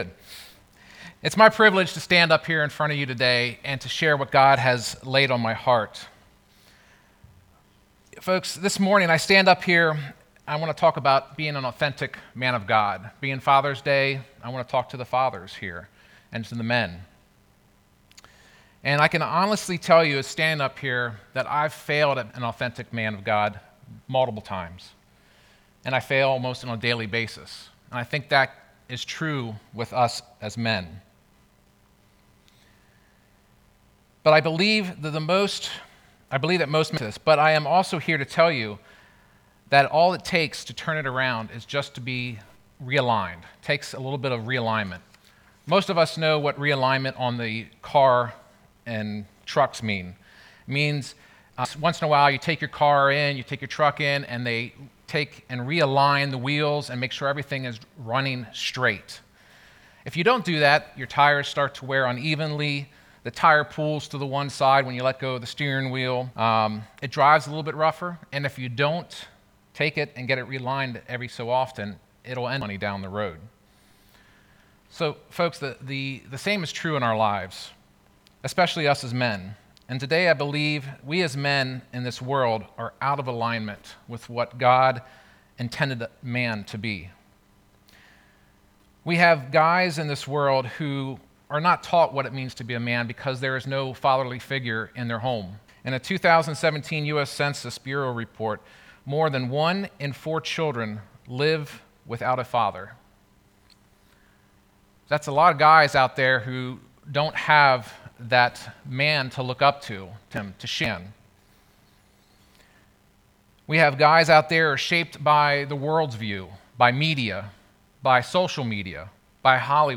Sermon-6.21.20.mp3